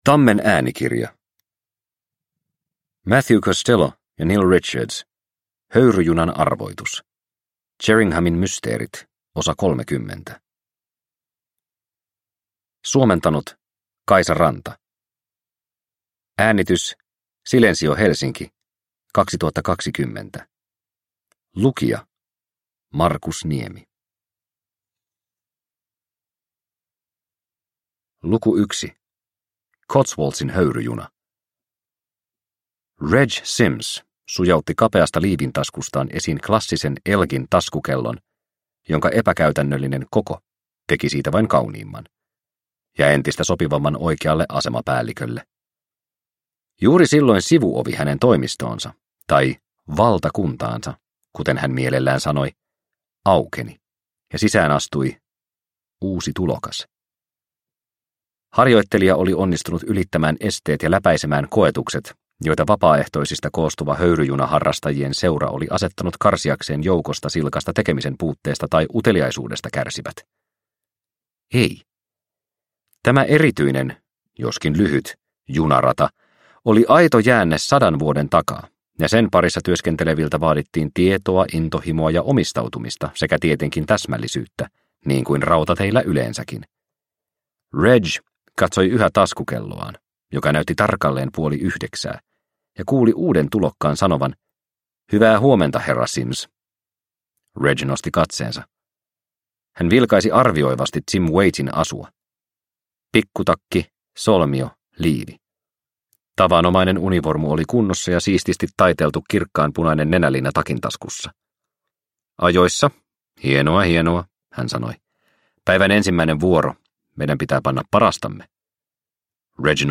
Höyryjunan arvoitus – Ljudbok – Laddas ner